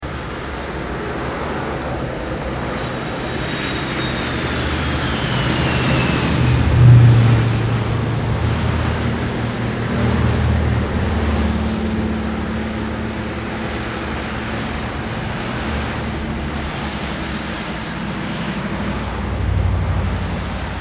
Passage d'avion avec bruit de fond
passage_avion_et_bruit.mp3